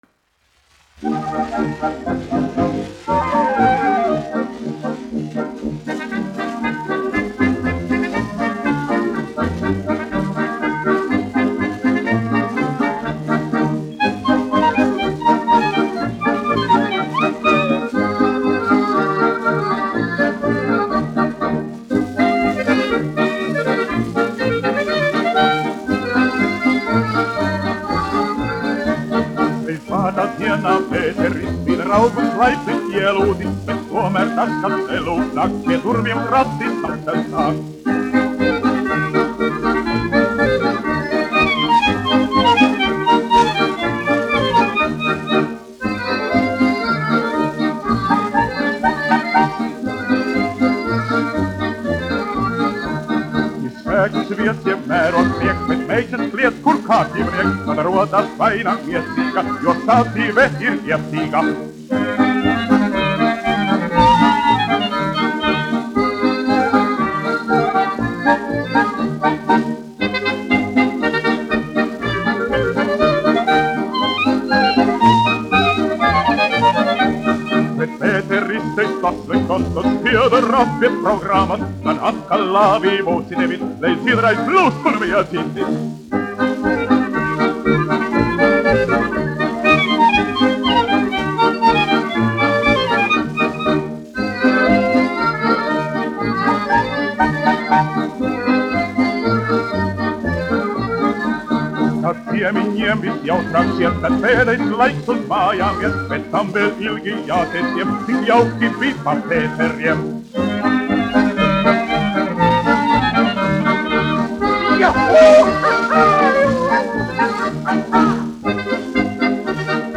1 skpl. : analogs, 78 apgr/min, mono ; 25 cm
Polkas
Populārā mūzika
Latvijas vēsturiskie šellaka skaņuplašu ieraksti (Kolekcija)